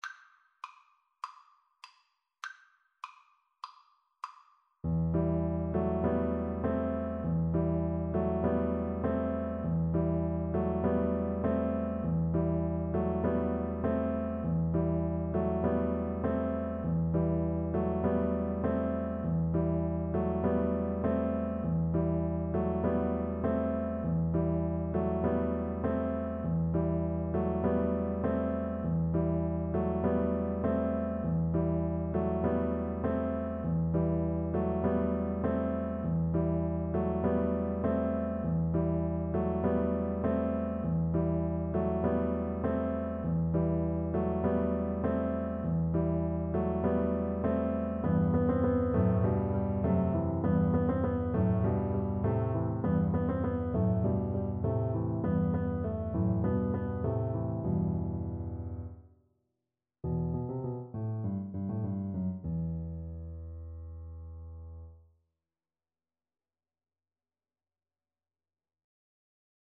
4/4 (View more 4/4 Music)
Allegro (View more music marked Allegro)
Classical (View more Classical Cello Music)